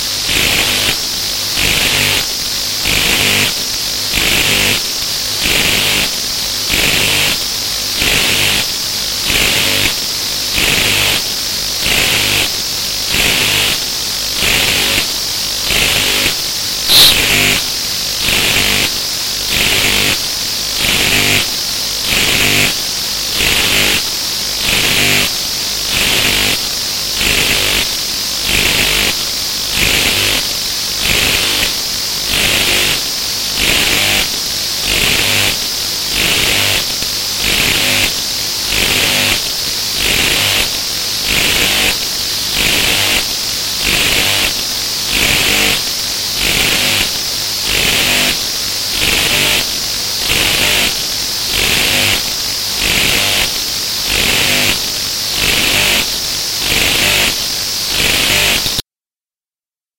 So how much of this is around today: To start with this is an image of an ultrasonic pest repellent gadget that emits ultrasound to deter mosquito’s.  You don’t hear a thing when this is on but the ultrasound microphone picks this silent sound and the software makes it audible. pest repellent sensor mp3
pest-repellent-sensor.mp3